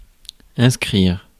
Ääntäminen
IPA : /ˈmʌs.tə/
IPA : /ˈmʌs.tɚ/